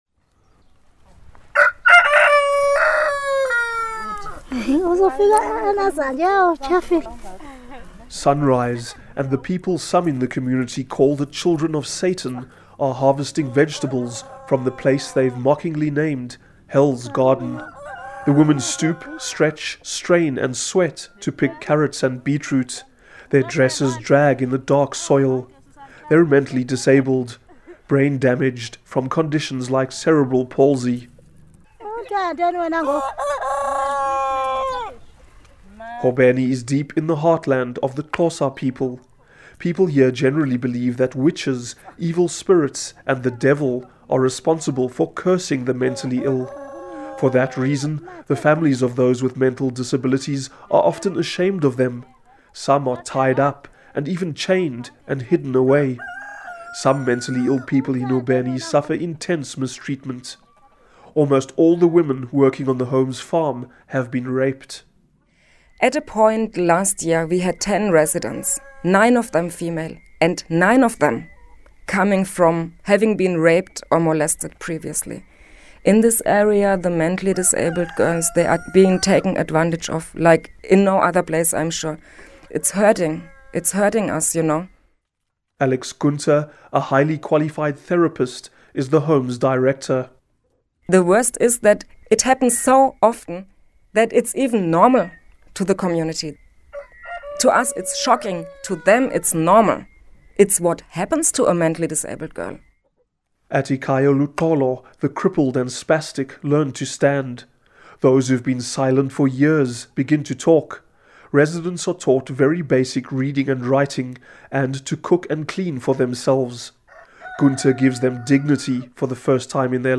Listen to report on vulnerability of mentally disabled to rape